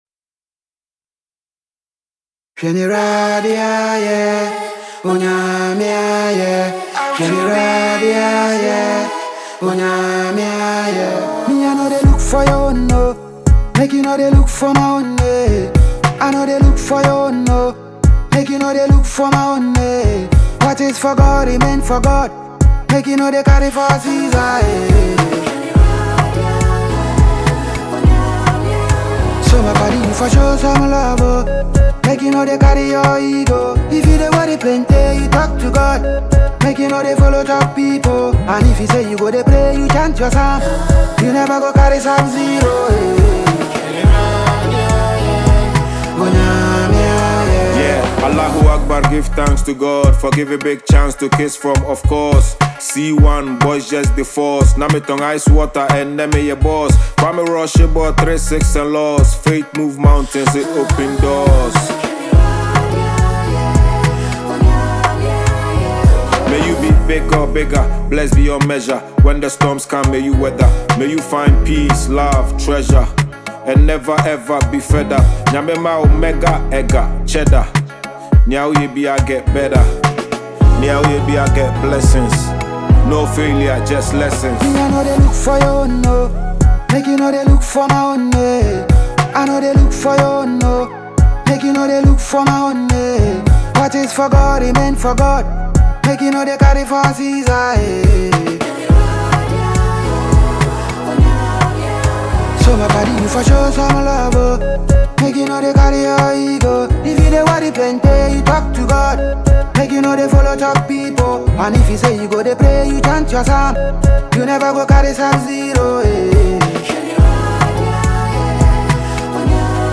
Genre: Afro-soul / Inspirational